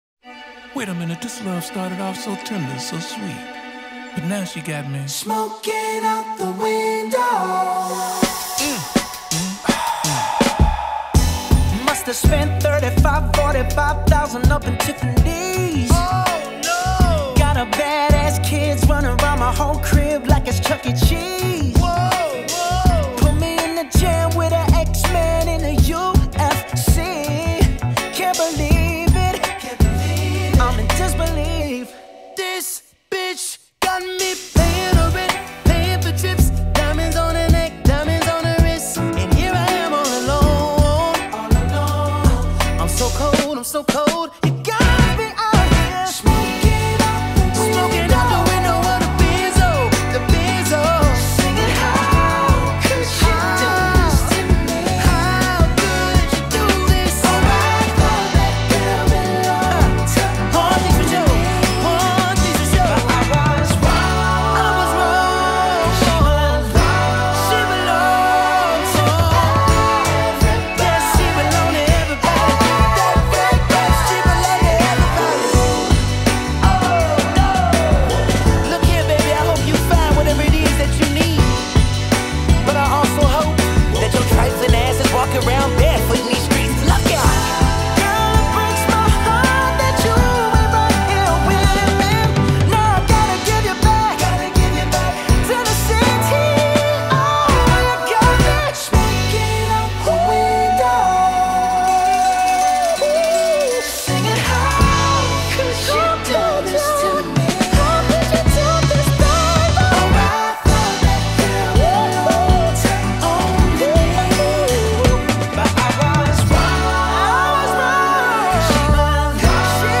BPM82
Audio QualityCut From Video